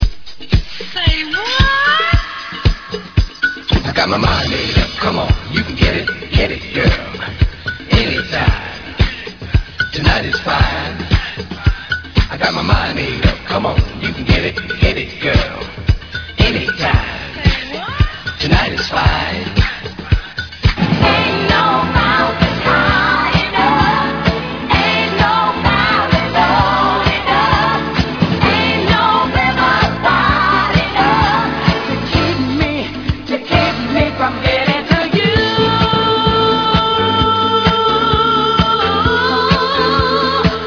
medley